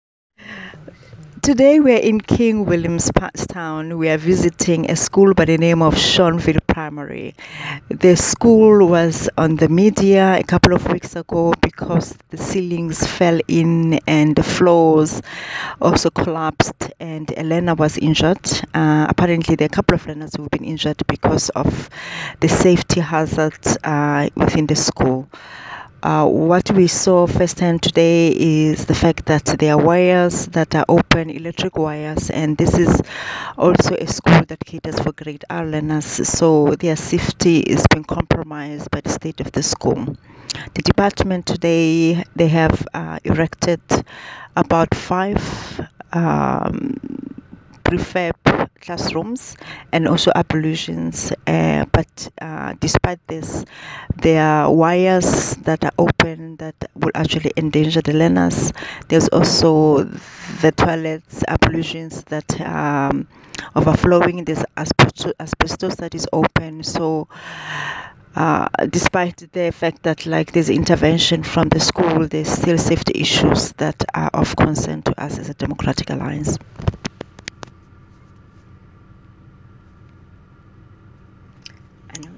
The statement below follows an oversight visit to Schornville Primary School by DA Shadow Deputy Minister of Basic Education, Nomsa Marchesi MP, and DA Shadow MEC for Education in the Eastern Cape, Edmund van Vuuren MPL. Please find attached soundbites by Nomsa Marchesi MP in